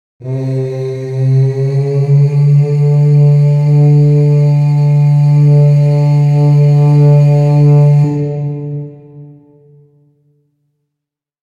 Soft Battle Horn Sound Effect
Cinematic-war-horn-sound-effect.mp3